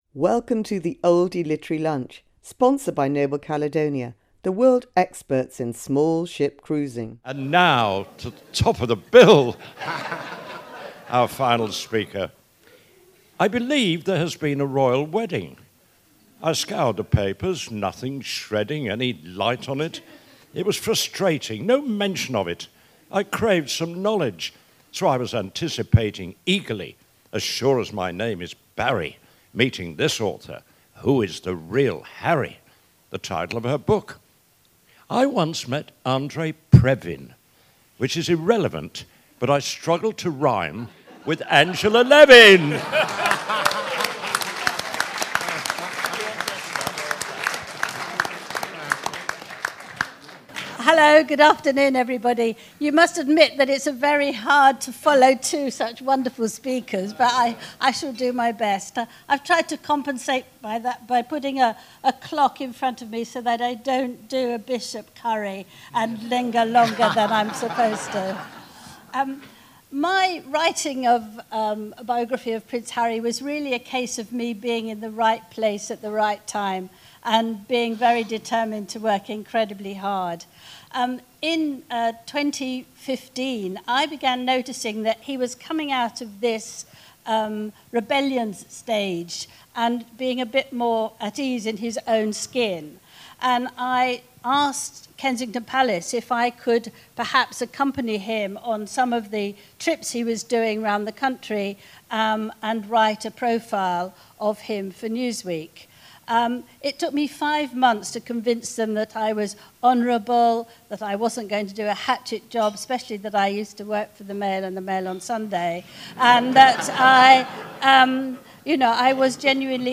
Conversations with the Prince at the Oldie Literary Lunch on June 5th 2018.